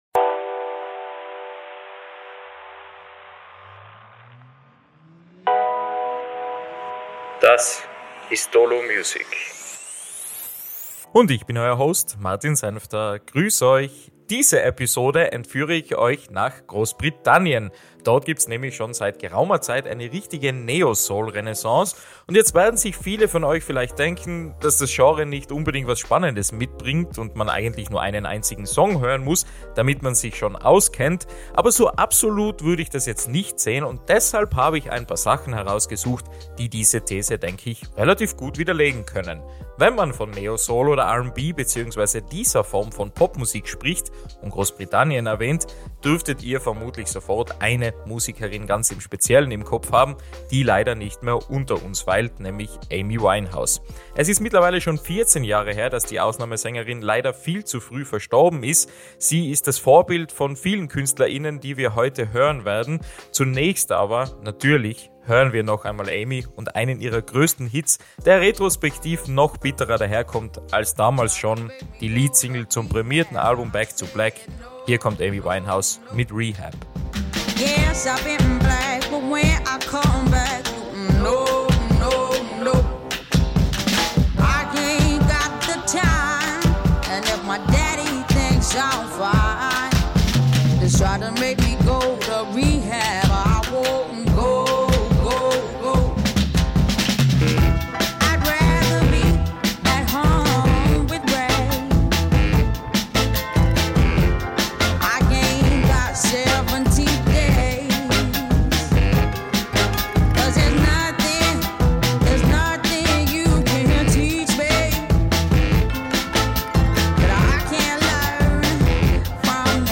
Ihre Songs sind sehr leichtfüßig, warm und natürlich auch emotional, verzichten aber auf Pathos oder Drama, zeichnen sich dafür aber durch Tiefgang aus.